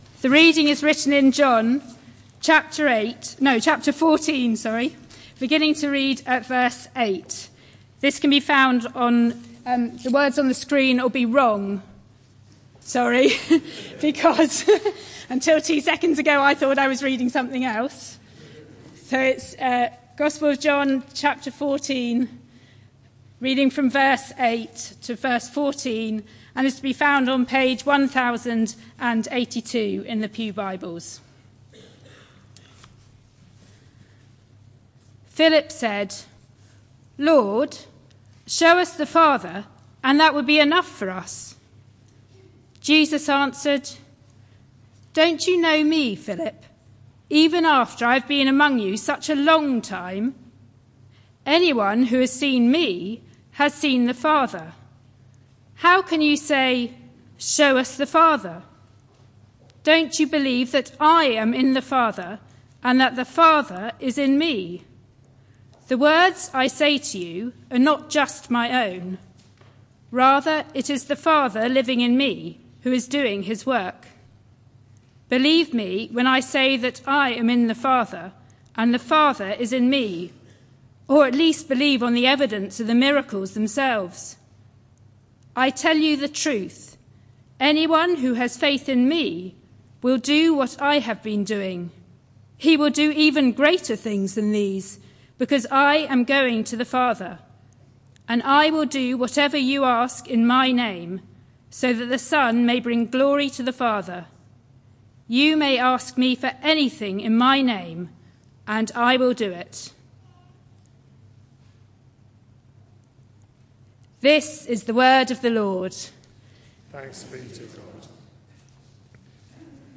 This sermon is part of a series: 10 January 2016